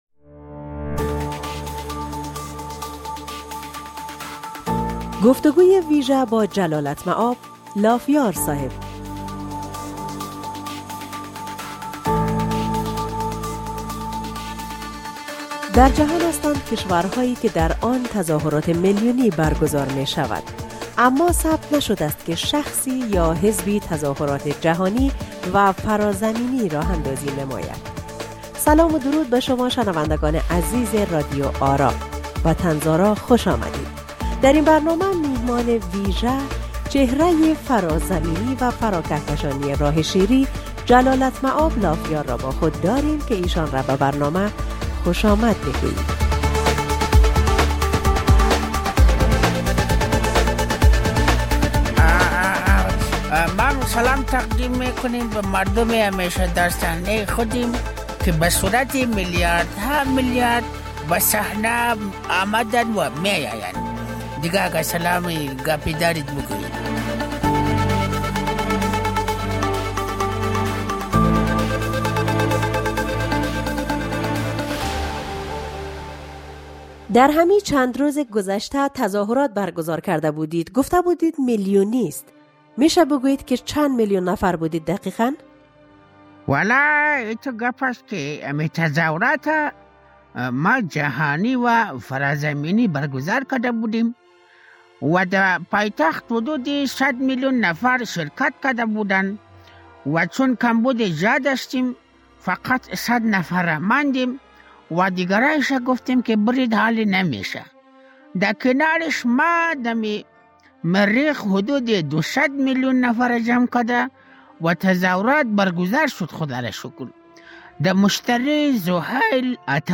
در اینجا نیز شما در قالب طنز، لاف‌های لاف‌یار صاحب را شنونده باشید.